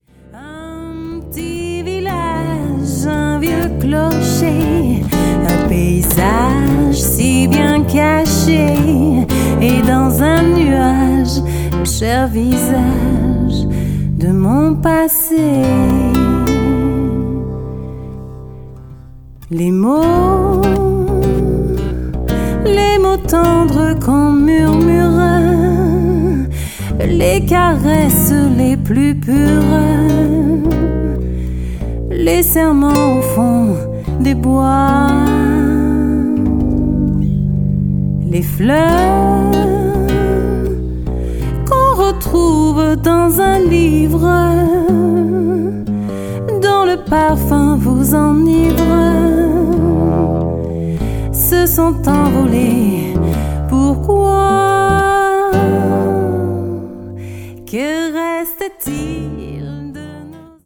bass
guitar
vocal
The whole without any special effect.